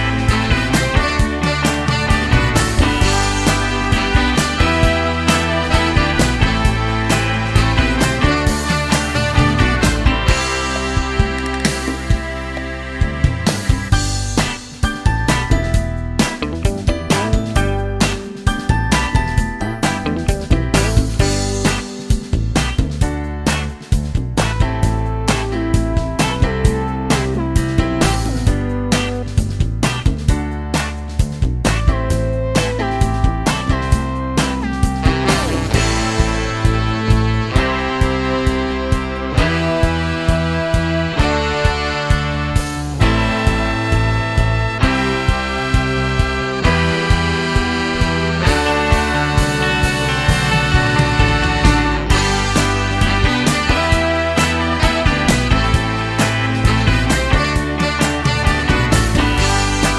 no Backing Vocals Duets 3:17 Buy £1.50